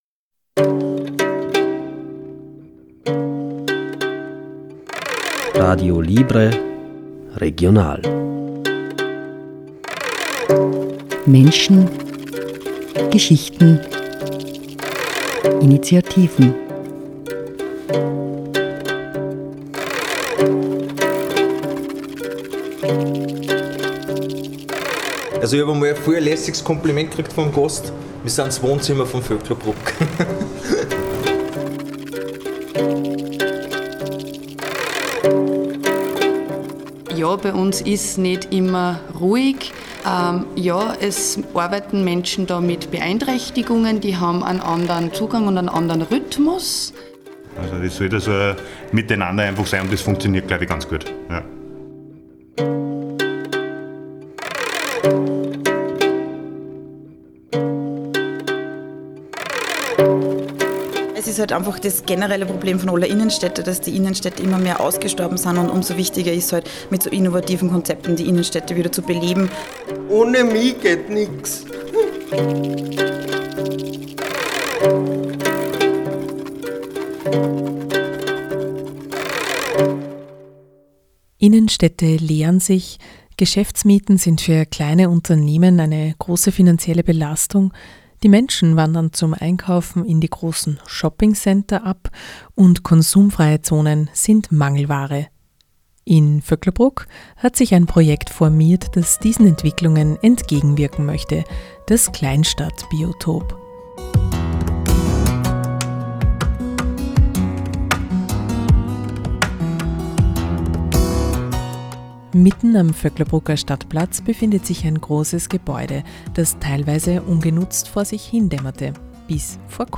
Das Kleinstadtbiotop ist ein Entwicklungsprojekt, in dem die Ideen blühen und gedeihen. Das FRS war vor Ort, um so viele wie möglich aufzuschnappen.